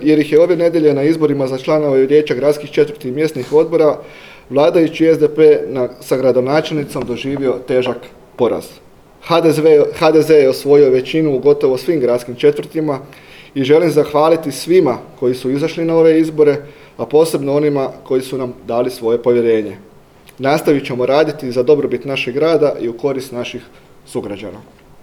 na danas održanoj tiskovnoj konferenciji